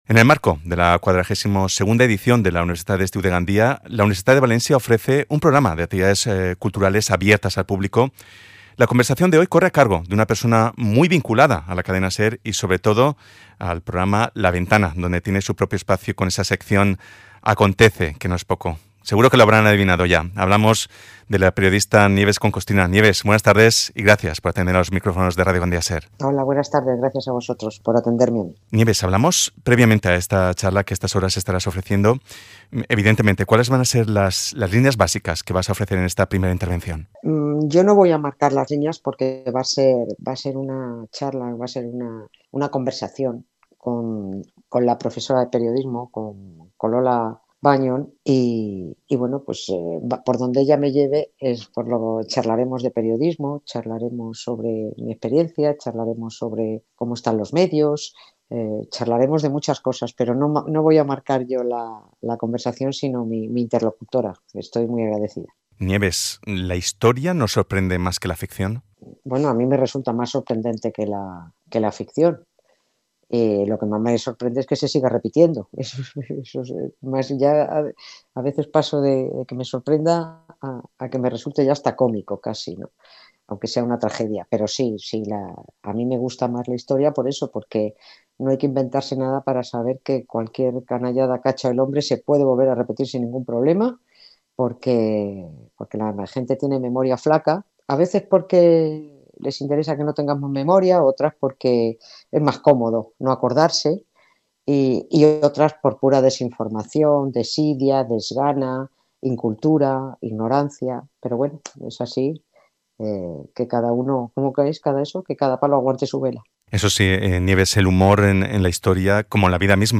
ENTREVISTA NIEVES CONCOSTRINA